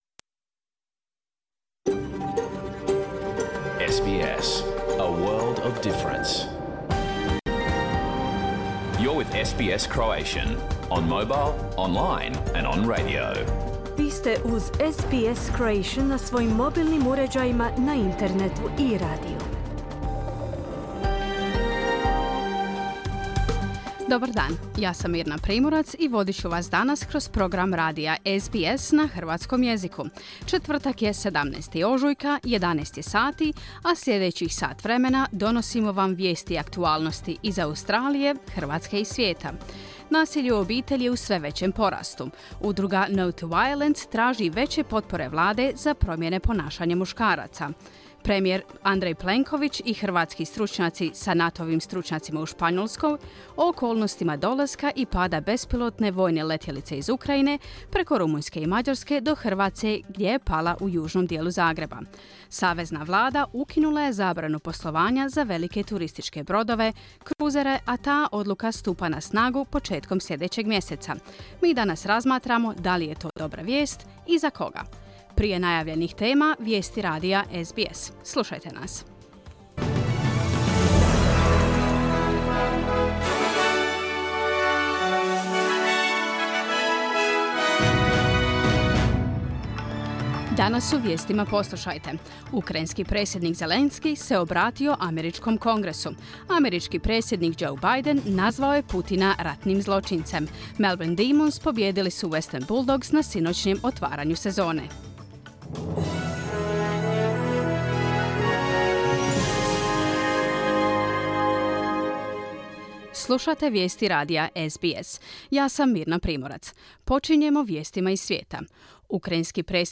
Cijelu emisiju, kako je emitirana uživo u terminu od 11 do 12 sati, možete poslušati i ovdje, bez reklama.